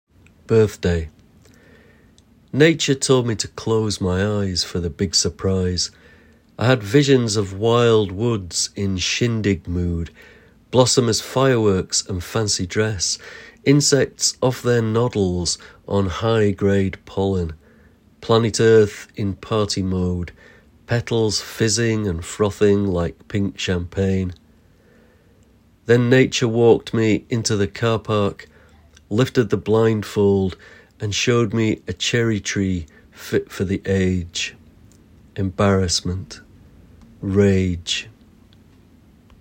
Poet Laureate Simon Armitage reads his poem Birthday. Birthday is part of Blossomise, a collection of poetry and music launched in 2024 to celebrate the arrival of spring.